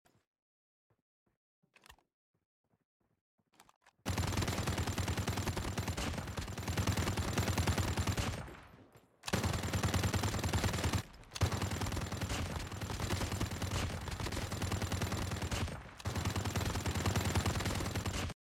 Doesn't the new USS 9 Smg have the best fire sound